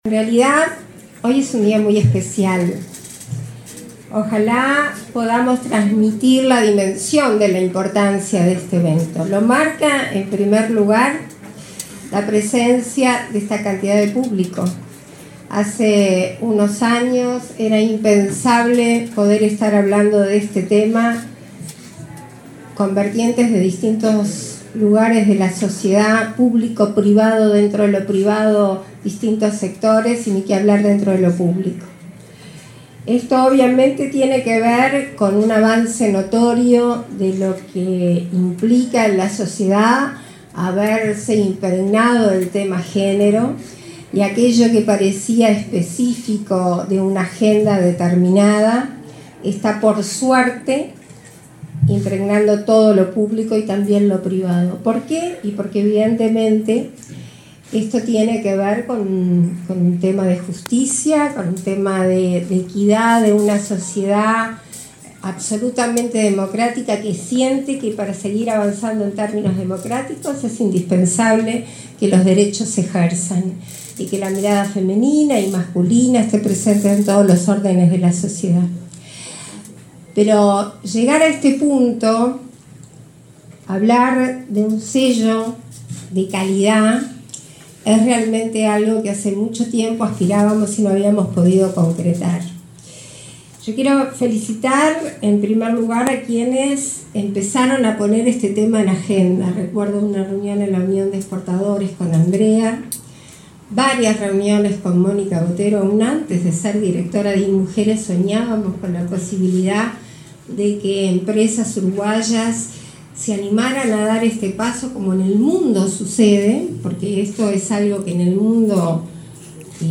Palabras de autoridades en presentación del Mides
Palabras de autoridades en presentación del Mides 22/03/2023 Compartir Facebook Twitter Copiar enlace WhatsApp LinkedIn El Instituto Nacional de las Mujeres (Inmujeres), del Ministerio de Desarrollo Social, presentó, este miércoles 22 en Montevideo, la cuarta versión del Modelo de Calidad con Equidad. La vicepresidenta de la República, Beatriz Argimón, y la titular del Inmujeres, Mónica Bottero, participaron en el evento.